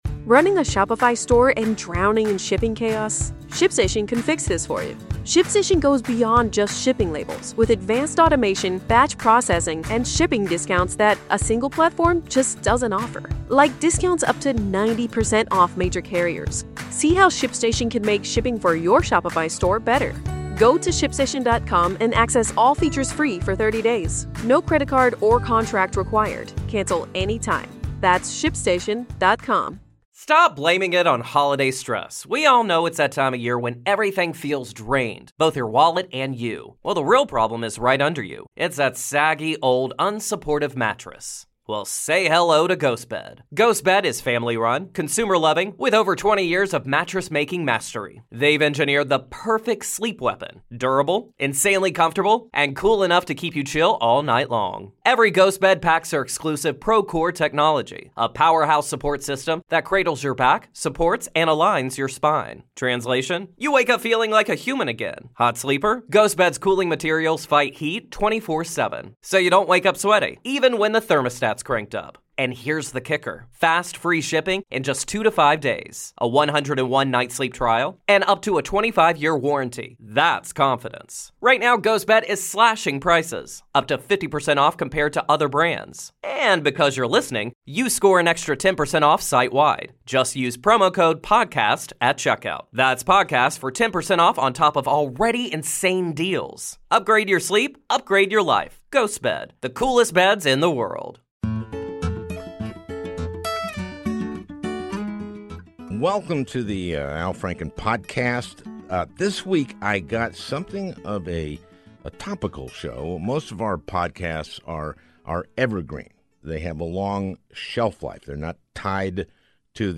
A Conversation with Dahlia Lithwick and Matt Miller
Dahlia Lithwick, brilliant writer for Slate on all things jurisprudential, and Matt Miller, former spokesman for Attorney General Eric Holder, have a lively discussion with an impassioned Al who argues that it’s time for the House to go to an impeachment inquiry or get off the pot.